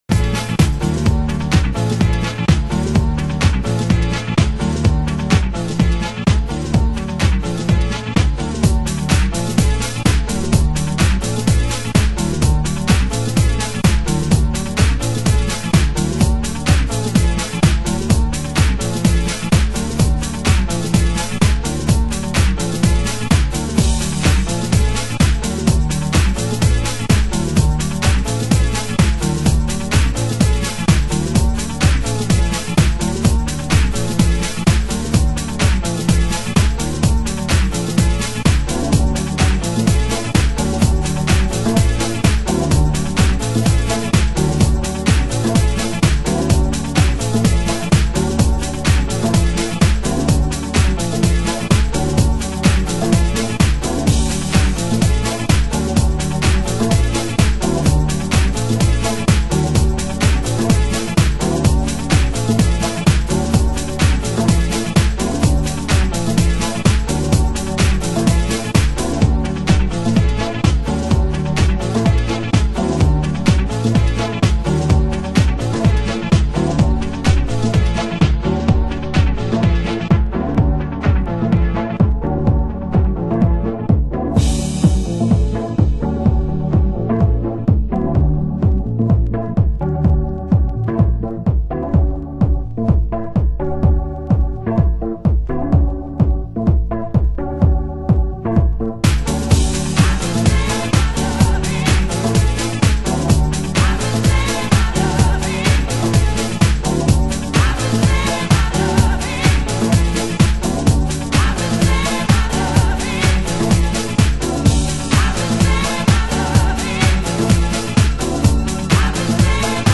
中盤 　　盤質：少しチリパチノイズ有